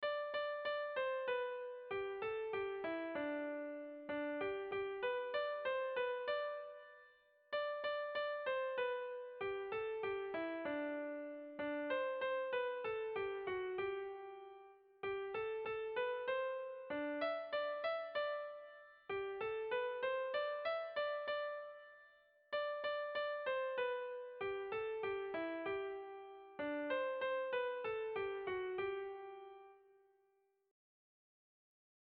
Sentimenduzkoa
Biba biba Euskera leloarekin txandatuz kantatzen da.
Zortziko handia (hg) / Lau puntuko handia (ip)